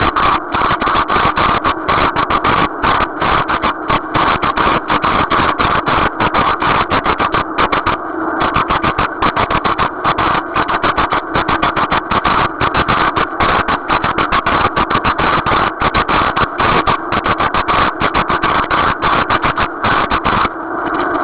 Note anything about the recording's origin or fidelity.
The following table has some recordings I made from the event.